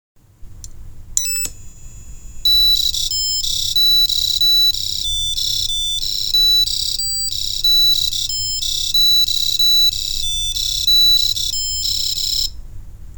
bwcはドミソの和音。bwgはシレソの和音になります。
なかなかそれっぽくなりました。